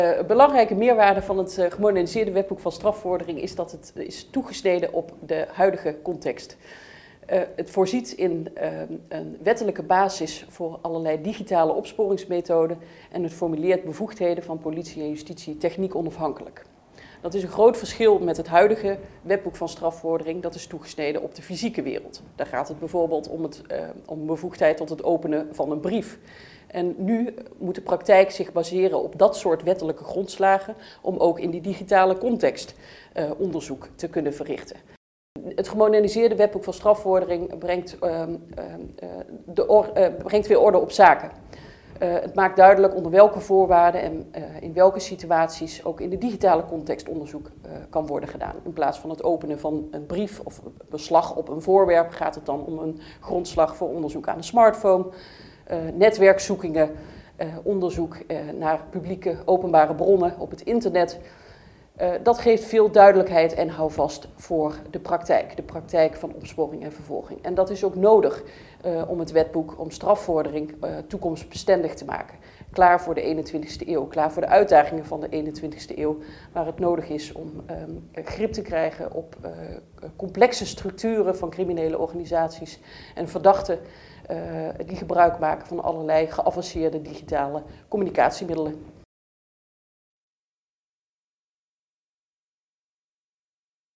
Audiobeschrijving